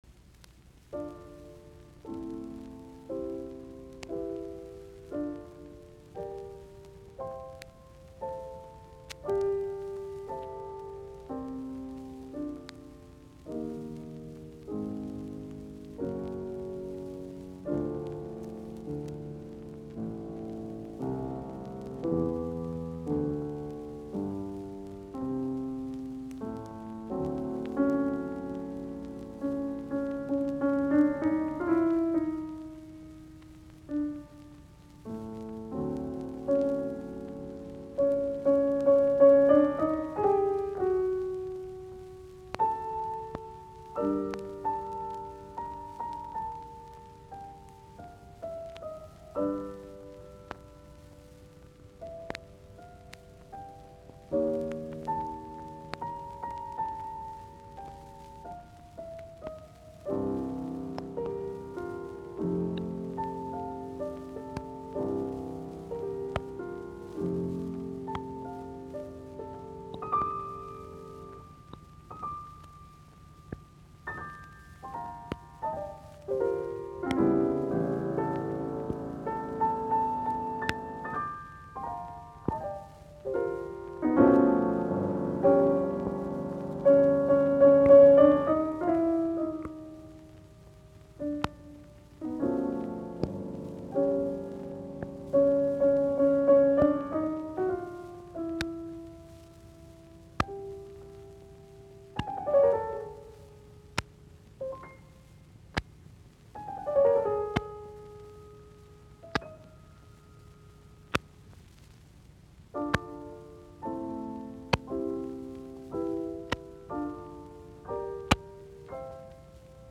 Soitinnus : Piano